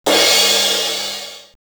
LM-2_CRASH_1_TL.wav